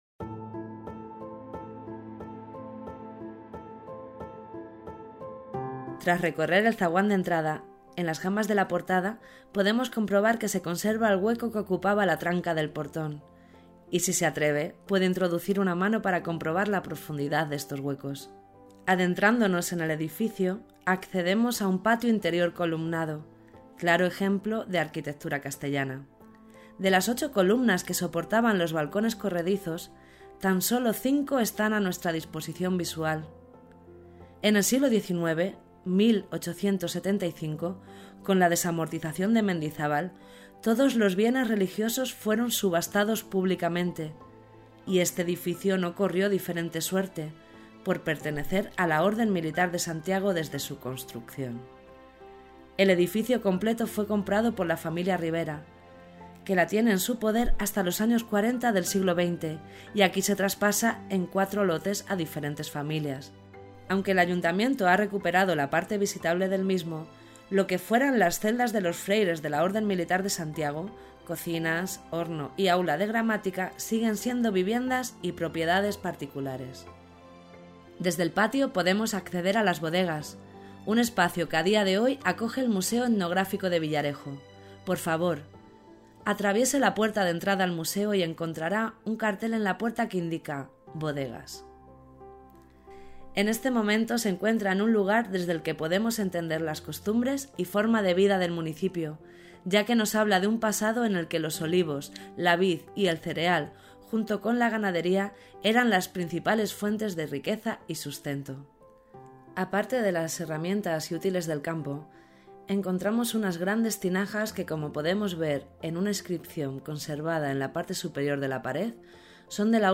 AUDIOGUÍA Puerta de acceso La Casa de la Tercia A la entrada de cada estancia encontrarás un código QR, que podrás escanear con tu teléfono móvil, para escuchar cada audioguía.…